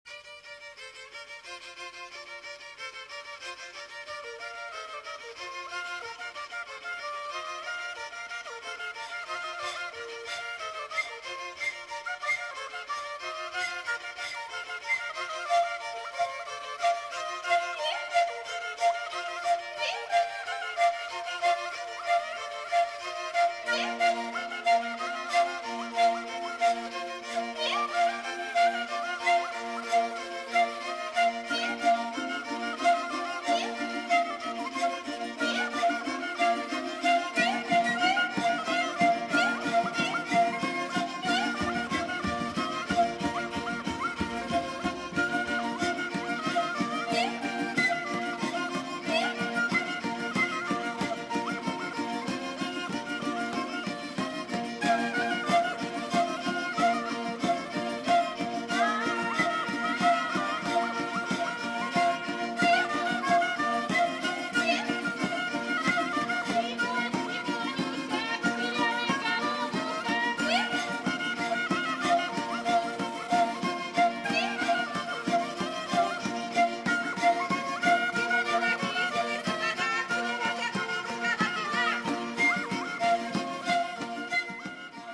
Kursk reg. instrumental dancing tune